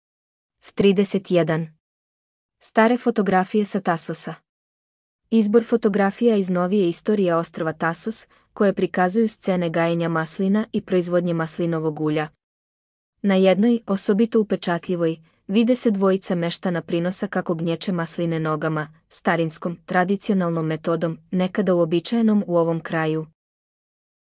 Аудио водич / Audio vodič.